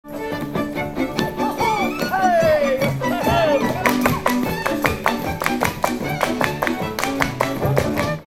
Műfaj Ugrós
Részl.műfaj Dus
Hangszer Zenekar
Helység Szany